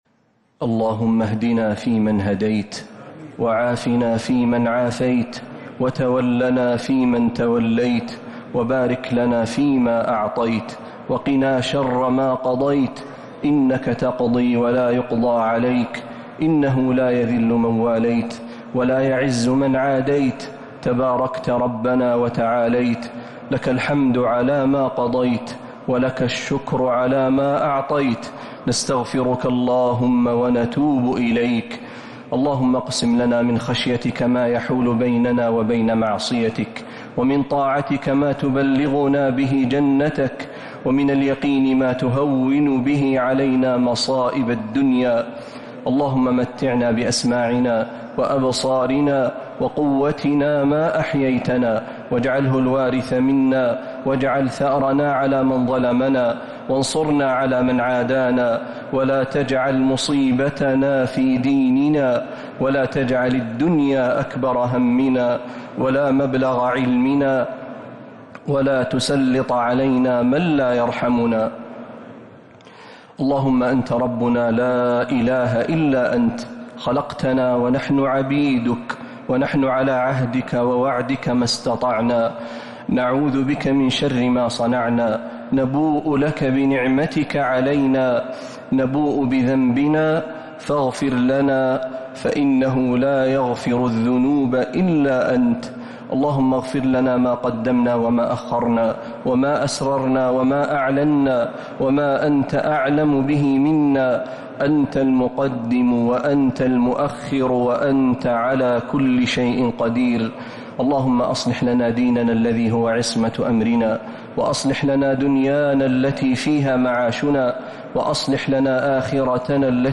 دعاء القنوت ليلة 22 رمضان 1447هـ | Dua 22st night Ramadan 1447H > تراويح الحرم النبوي عام 1447 🕌 > التراويح - تلاوات الحرمين